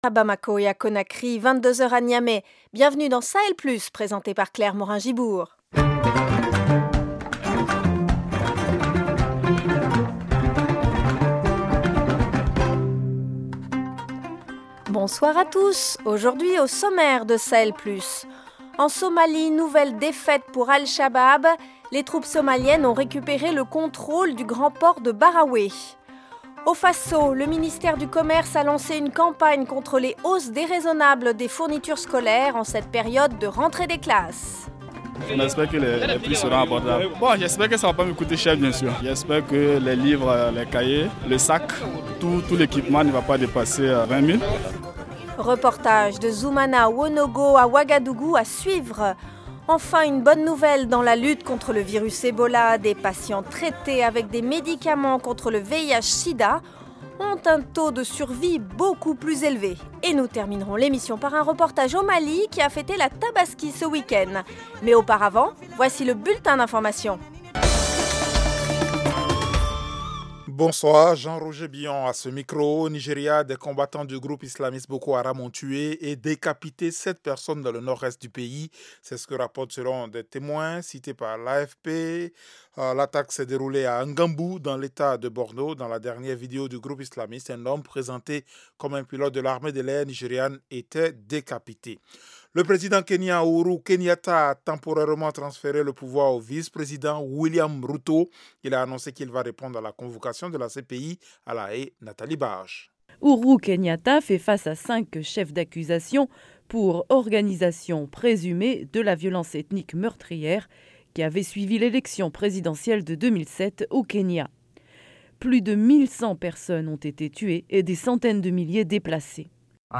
Ebola : de nouveaux traitements sont testés. Reportage au Mali pour la fête de la Tabaski.